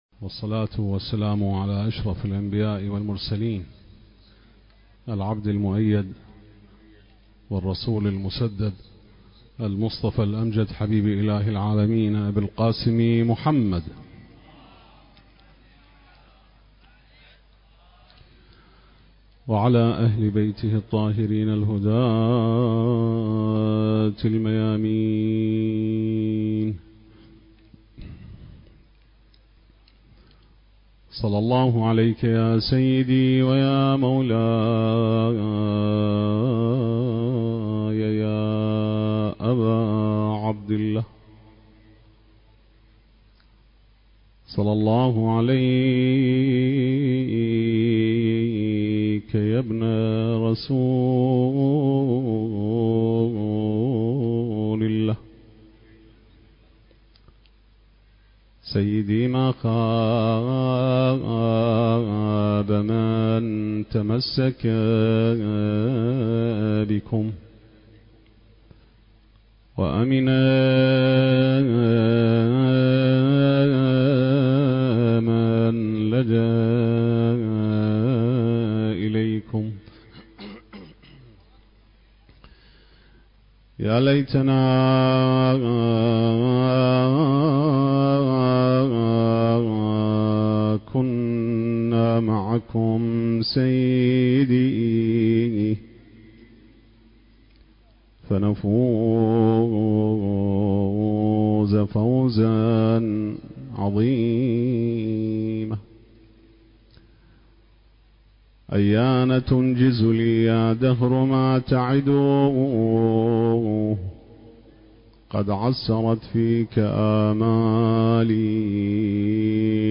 المكان: مخيم الشباب الحسيني - شباب الكرادة الشرقية - بغداد التاريخ: ليلة 8 من صفر الخير 1445 هـ